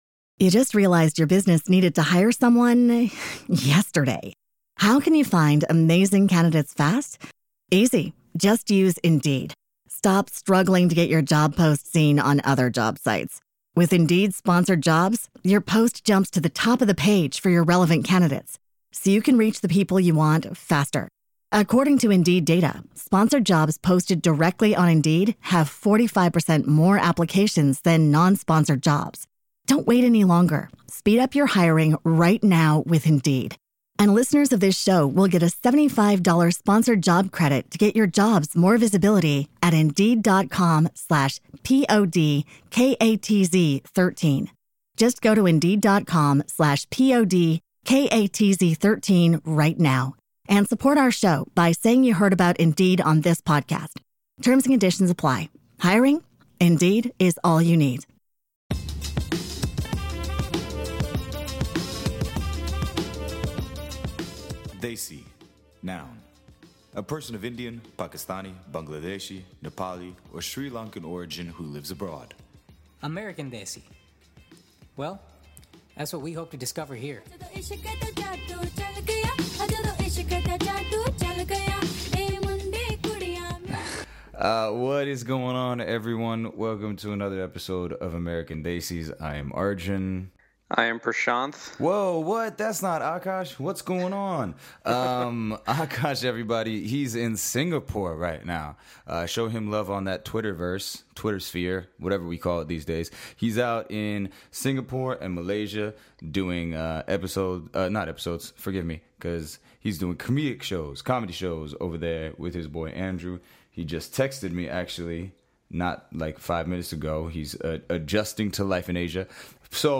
Well in that episode we did more of a conversation than traditional interview and for this one we followed that model.